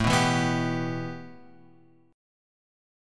A 7th Suspended 2nd